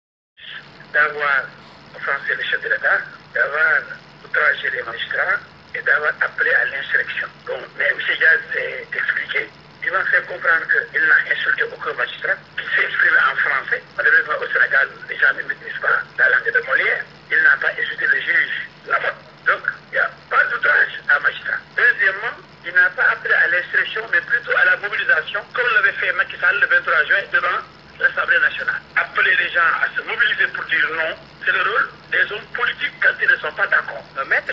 Me El Haj Diouf exige ainsi la libération immédiate de son client, dans un entretien téléphonique avec la Rfm...